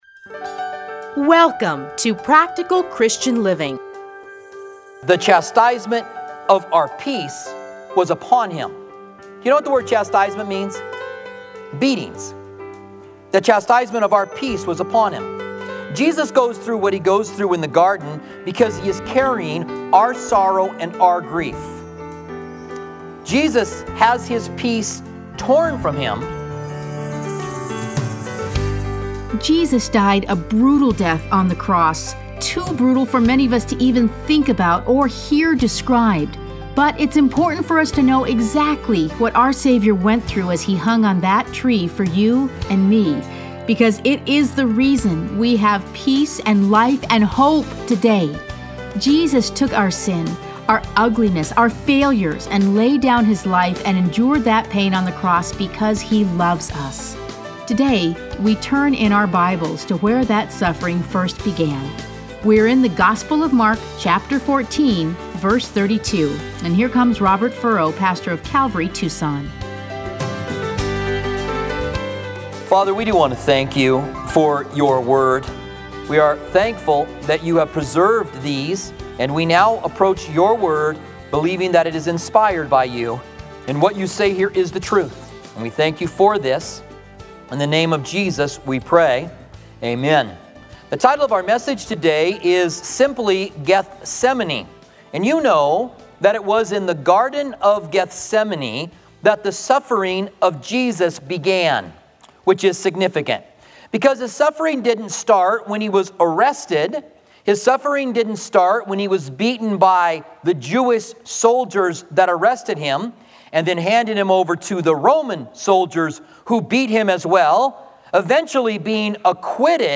Listen to a teaching from Mark 14:32-41.